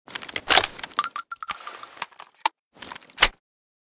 toz34_reload_empty.ogg.bak